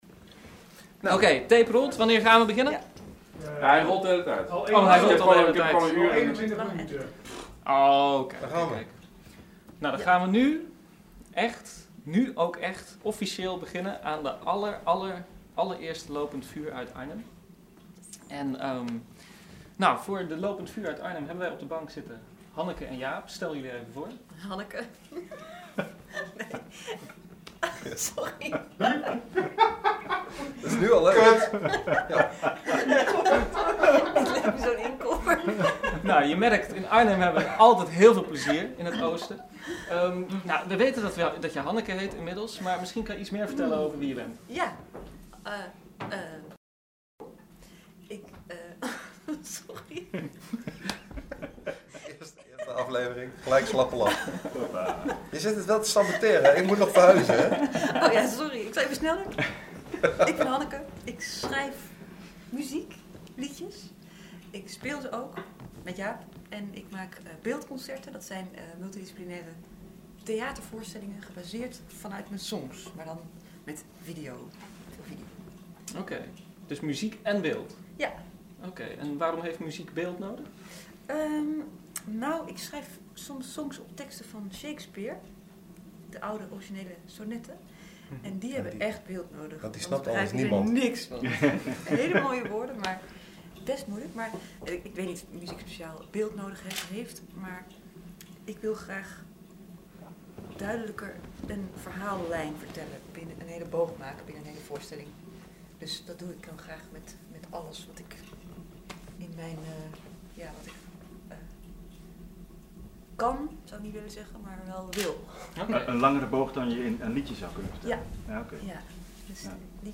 In de eerste podcast vanuit Arnhem een goed gesprek
Tussendoor bovendien drie prachtige nummers: